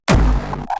有效果的硬核踢球
描述：硬核踢腿，预先添加了音效。 复杂踢腿。 Fl studio 20对此的其他设置： Boost Clip。18 25 环形调制。33 环形调制频率：38 滤波器谐振：15 Pogo效果：从100到15。
标签： 130 bpm Hardcore Loops Drum Loops 138.26 KB wav Key : Unknown FL Studio
声道立体声